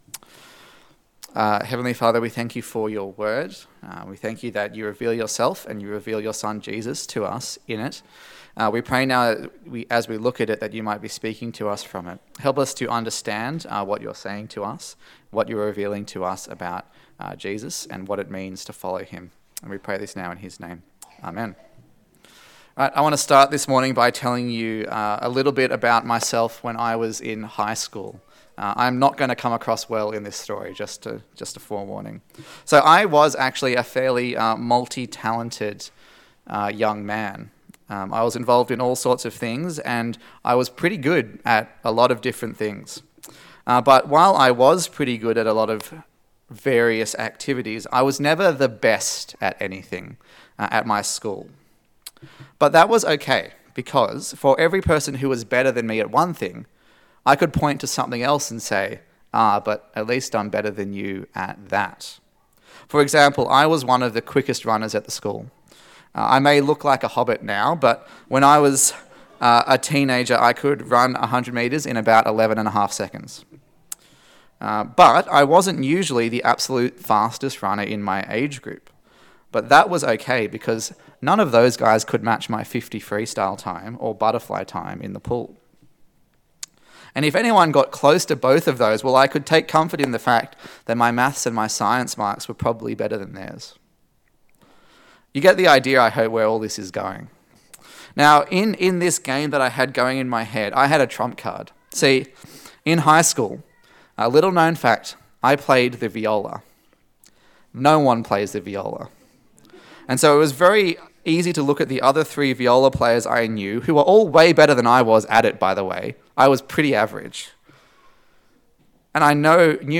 Luke Passage: Luke 14 Service Type: Sunday Service